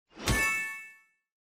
star_sound3.mp3